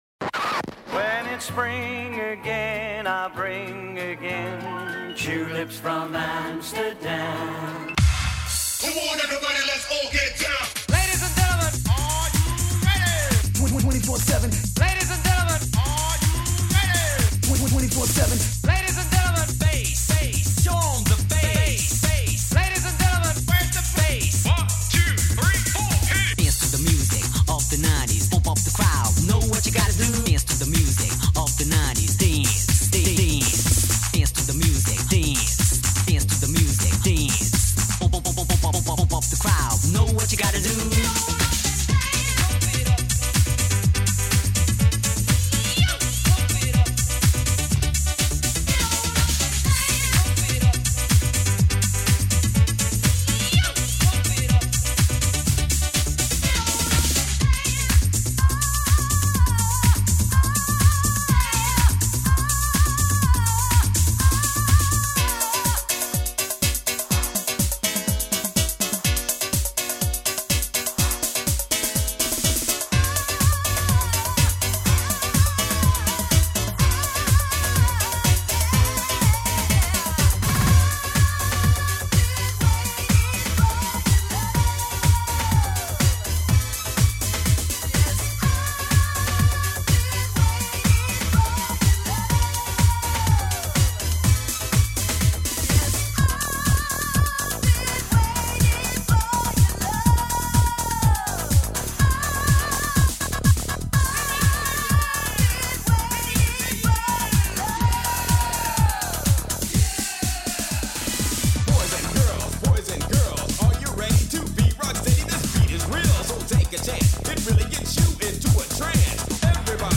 when djs got a hold of amigas